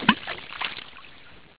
BallHazard.wav